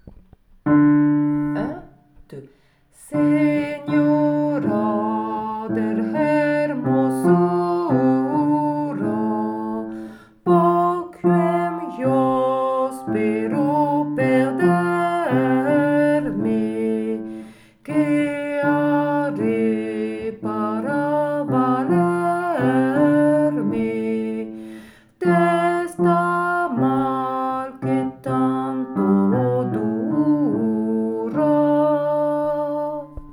Version à écouter pour la prononciation et la mise en place des syllabes
Basse
senora-de-hermosura-basse.wav